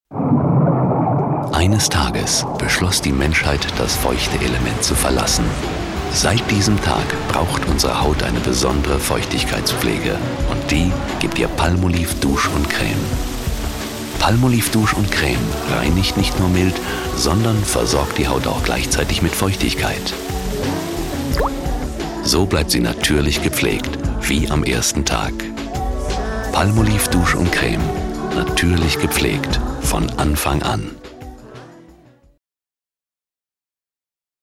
Profi-Sprecher deutsch. Warme freundliche Stimme, Imagefilme, Dokumentationen
norddeutsch
Sprechprobe: eLearning (Muttersprache):
german voice over artist